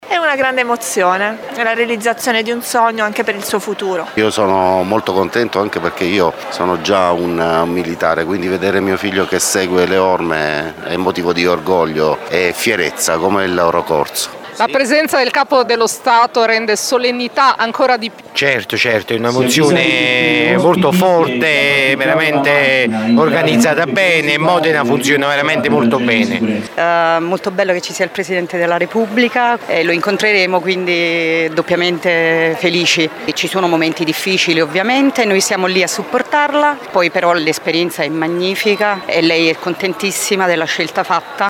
Ma sentiamo alcuni parenti dei cadetti presenti alla manifestazione: